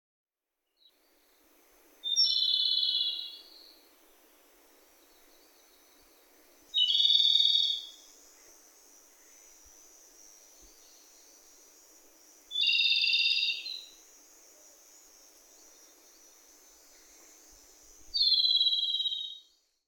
３　コマドリ（駒鳥）　全長約14cm
コマドリの声量たっぷりのさえずりが多数の地点で響き渡っていた。
【録音6】 2025年8月5日　日光白根山
さえずり・・・馬のいななきを思わせる「ヒンカラカラカラ」